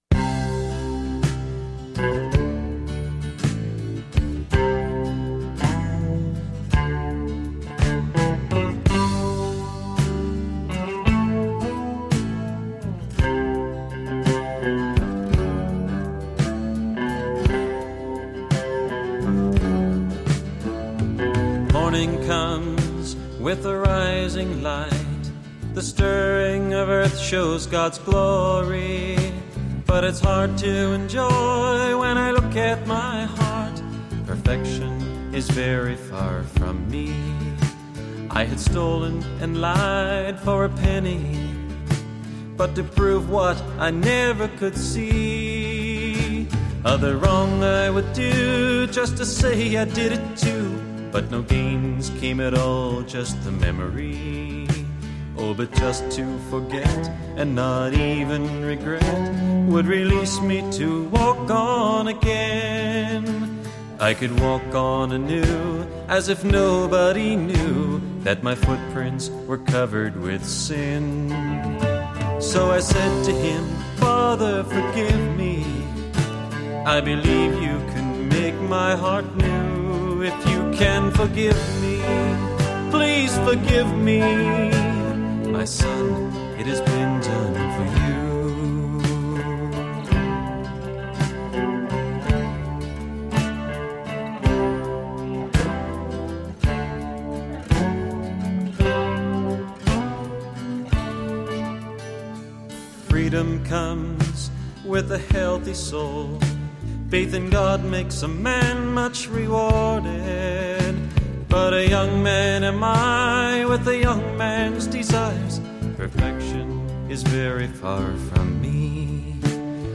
Piano
Electric & acoustic guitars
Bass guitar
Percussion
Trumpet
English horn, saxophone and clarinet
Synthesizer
Background vocals